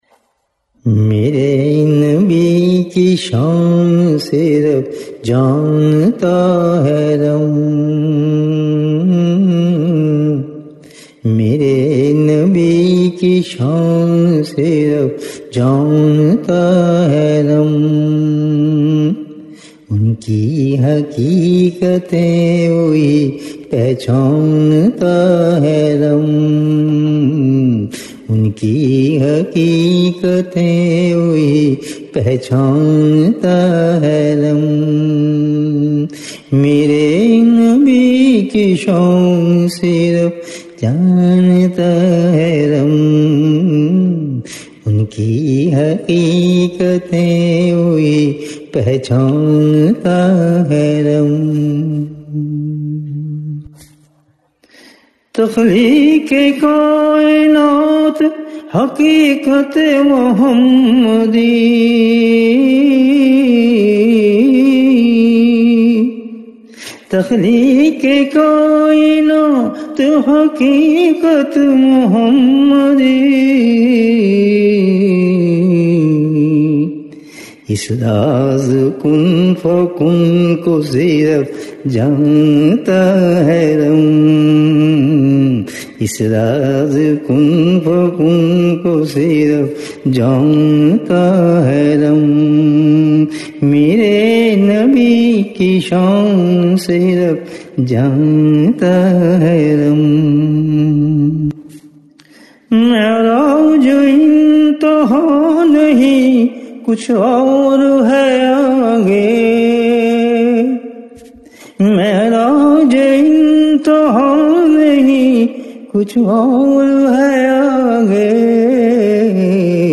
Subhanallah great Naat